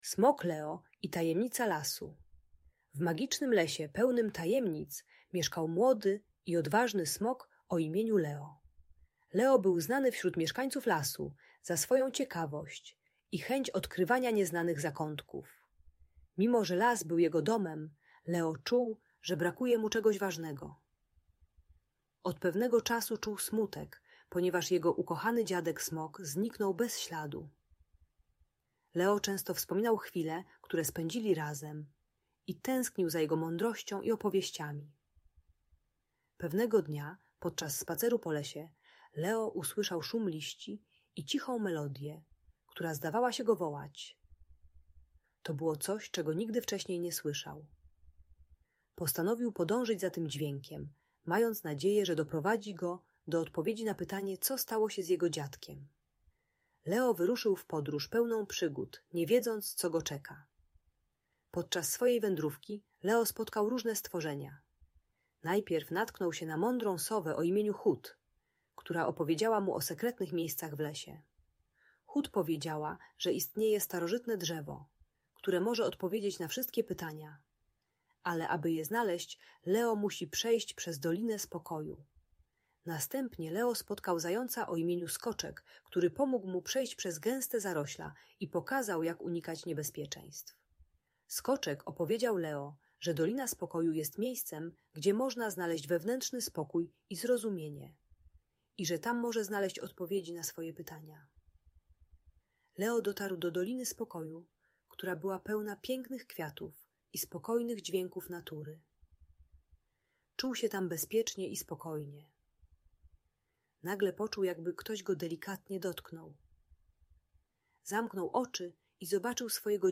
Opowieść o Smoku Leo i Tajemnicach Lasu - Lęk wycofanie | Audiobajka
Audiobajka o radzeniu sobie ze stratą i smutkiem po śmierci bliskiego.